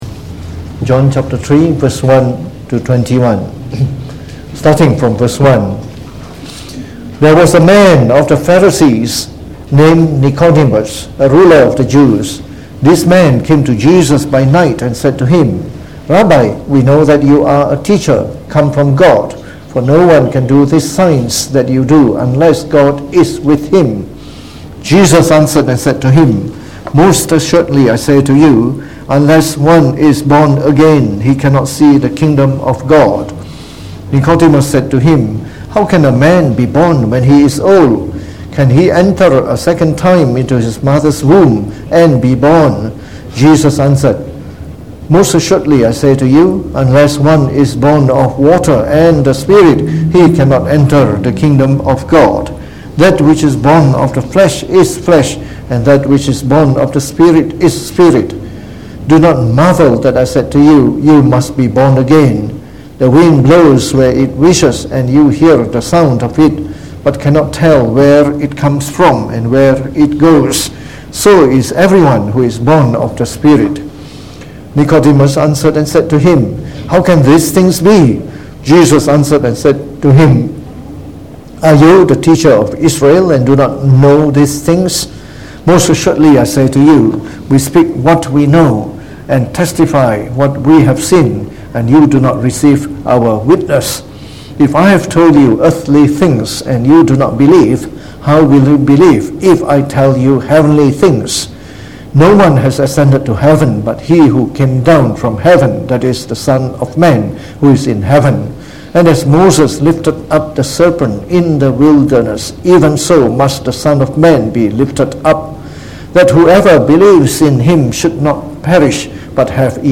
Preached on the 11th of Sept 2019.
in the evening of our usual Bible Study night